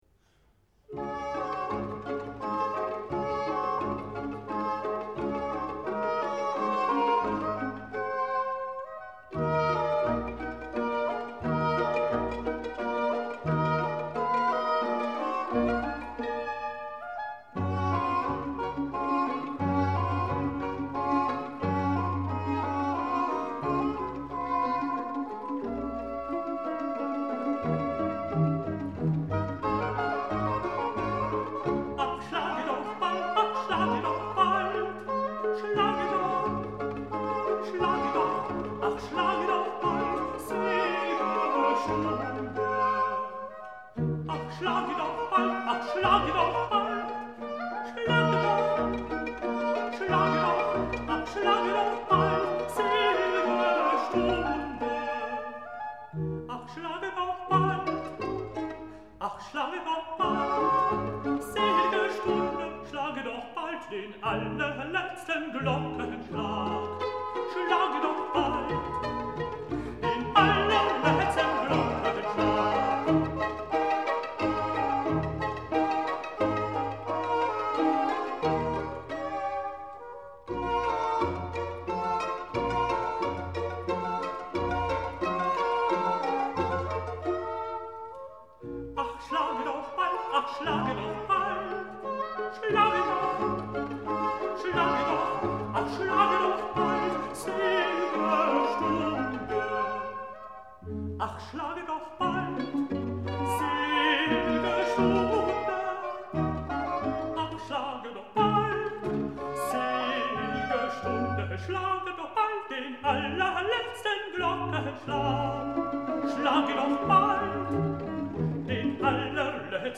Soli : S T B, Chœur : S A T B, Cor, Hautbois I/II, Hautbois d’amour I/II, Violons I/II, Violes, Continuo